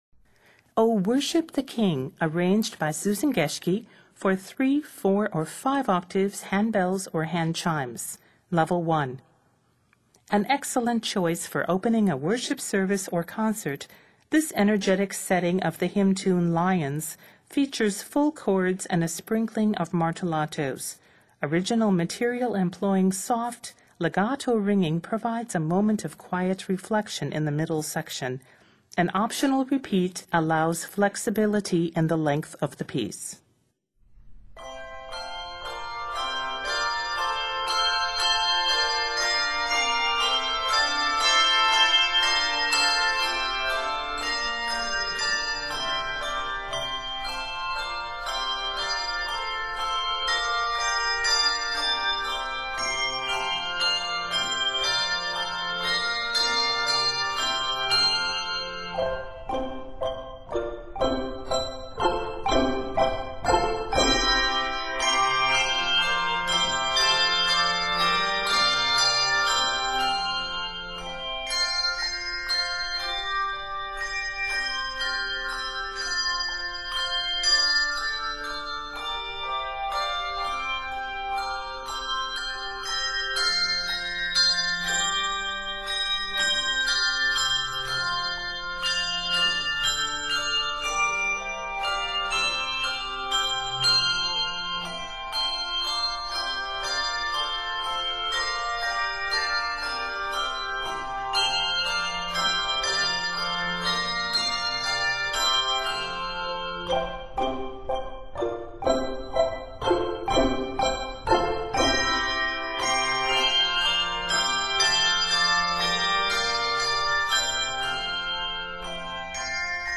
energetic setting
Set in F Major, measures total 46.